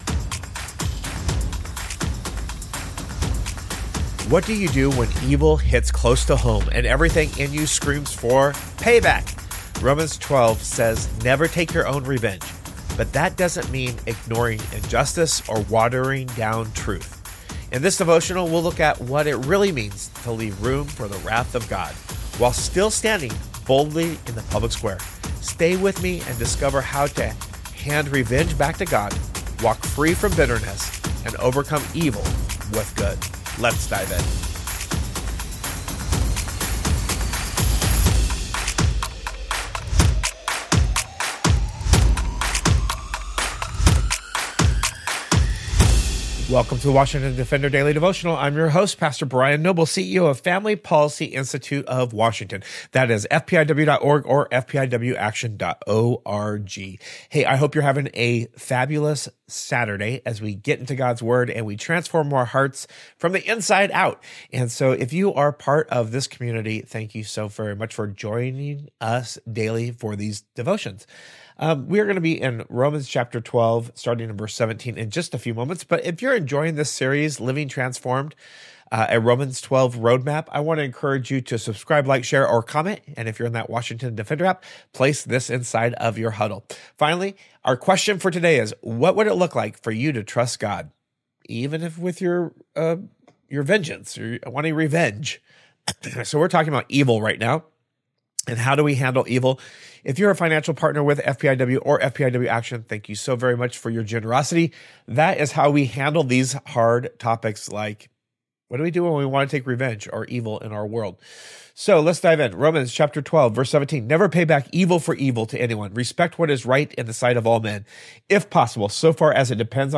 Romans 12 says, “Never take your own revenge,” but that doesn’t mean ignoring injustice or watering down truth. In this devotional, we’ll look at what it really means to leave room for the wrath of God while still standing boldly in the public square.